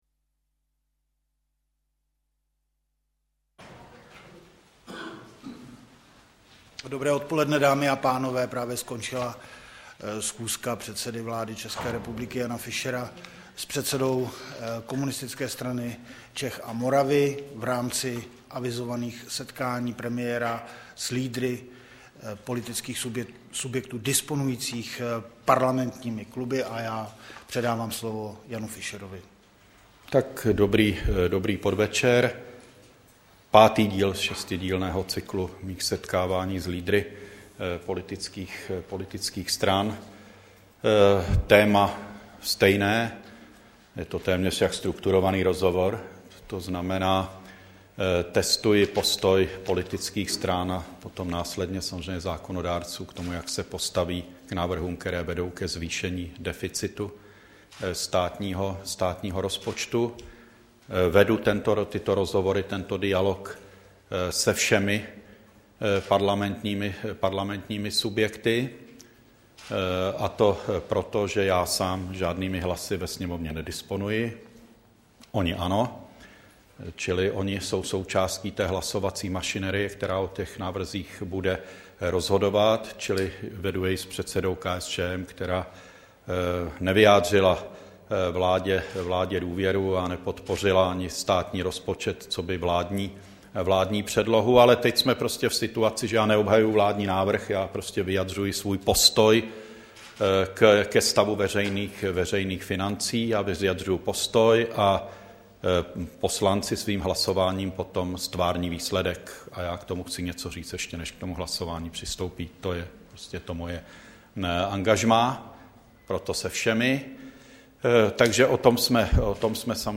Tiskový brífink po jednání s Vojtěchem Filipem, 14. ledna 2010